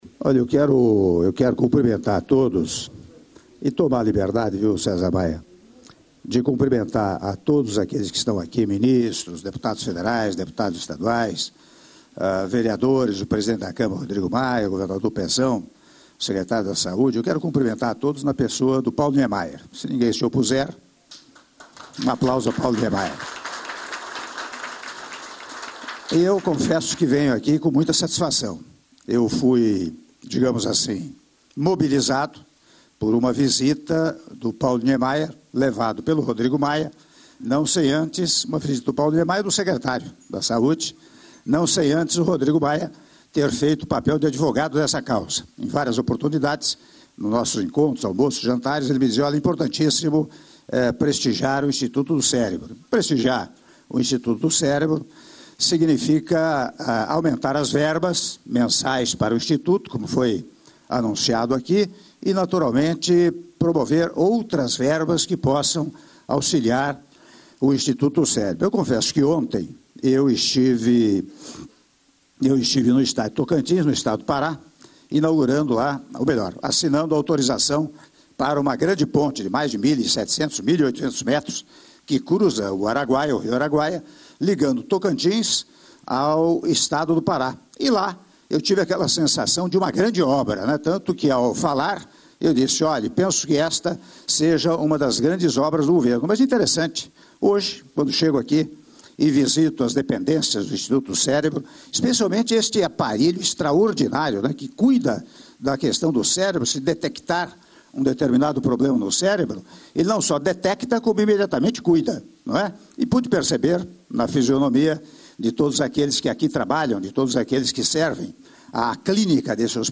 Áudio do discurso do Presidente da República, Michel Temer, na cerimônia de anúncio de liberação de recursos para o Instituto Estadual do Cérebro Paulo Niemeyer (IEC) - Rio de Janeiro/RJ -(04min39s)